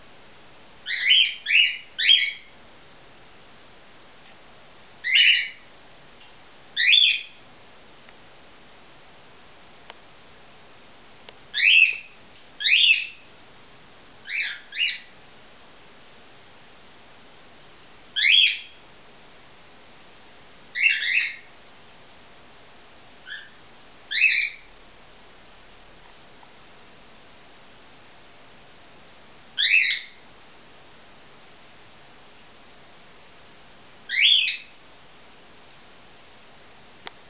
Aktive Vögel
Im Schwarm herrscht dann ein reges Treiben und die Geräuschkullise ist für empfindliche Ohren zu laut.
Dieser Schwarm lebt sich am frühen Morgen voll aus.
aufregung.wav